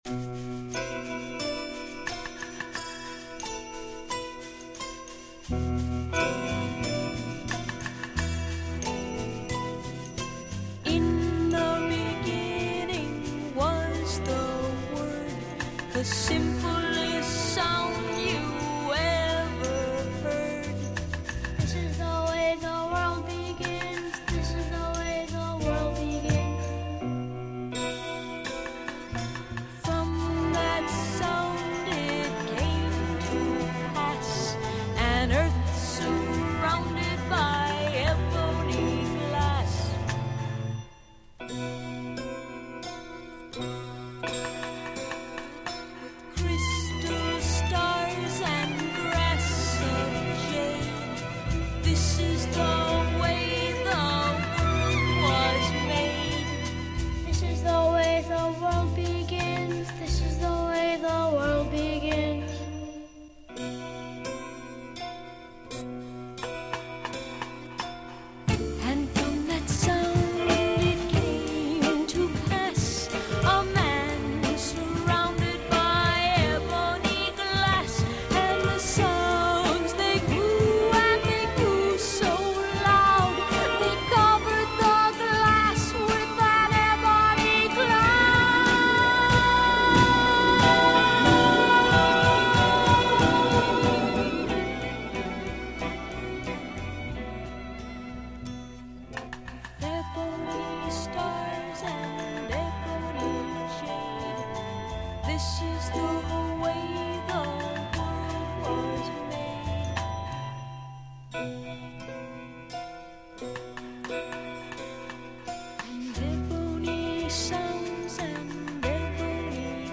the songs float from sort of late-sixties hippie funk
including a pretty creepy kid